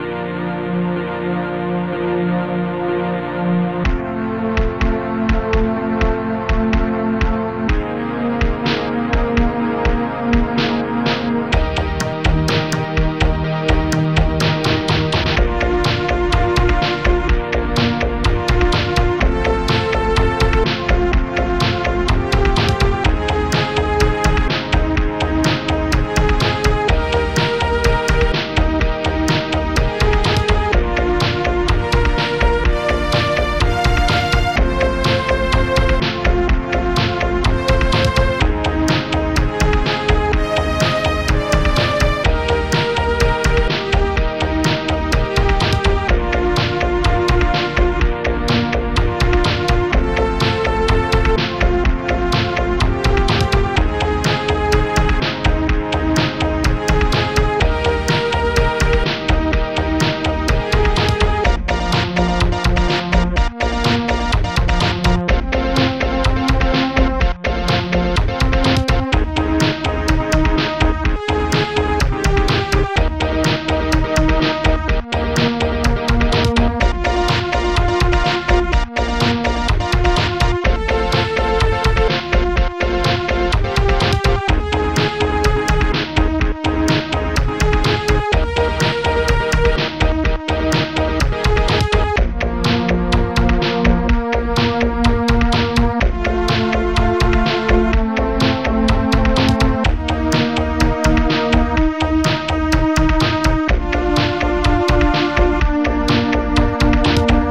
Protracker Module
Instruments st-27:ebass25 st-25:bassdrum-super1 st-32:snare18 st-32:synthstrings4 st-22:strings2loop